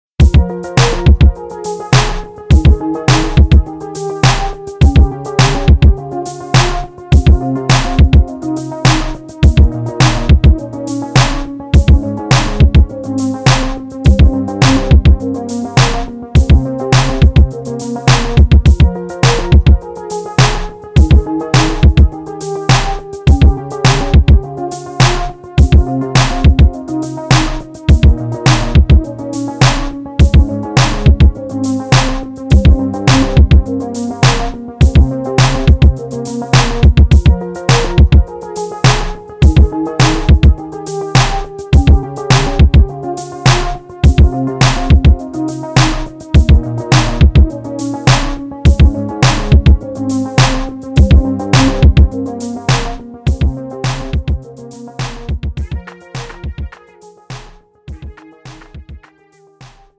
장르 pop 구분 Premium MR
Premium MR은 프로 무대, 웨딩, 이벤트에 최적화된 고급 반주입니다.